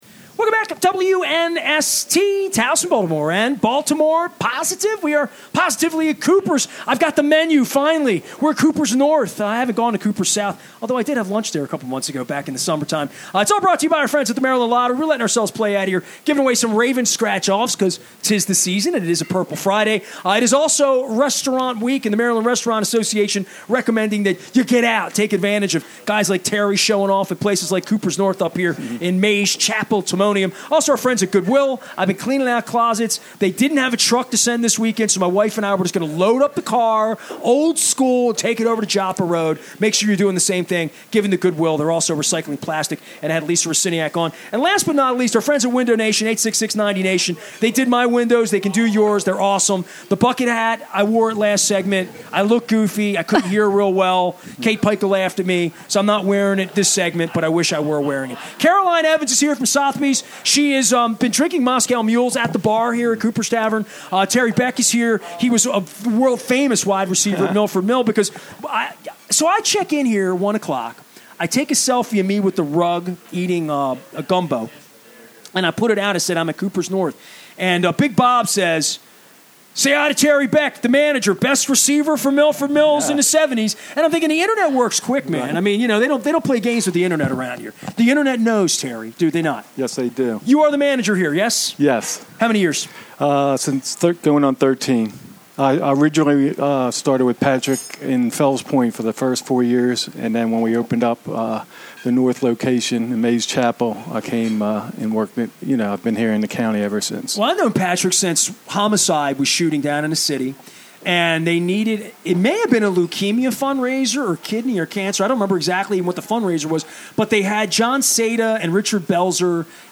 from bar at Kooper's North